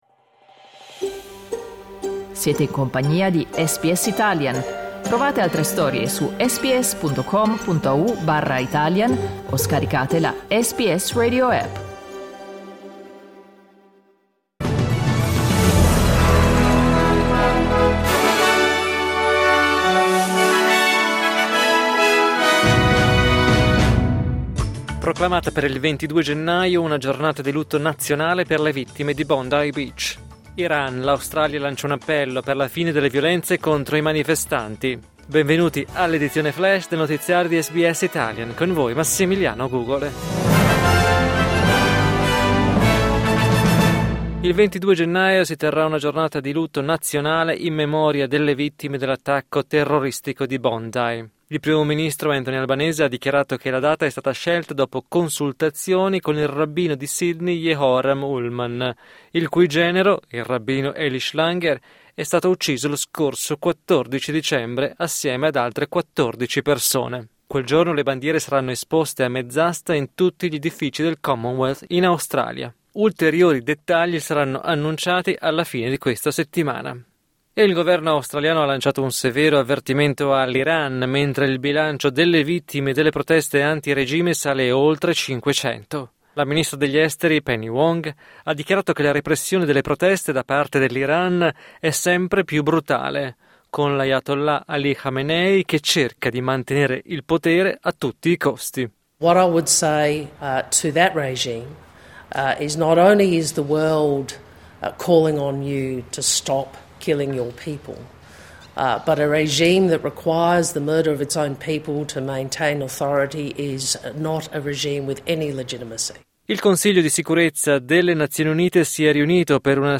News flash martedì 13 gennaio 2026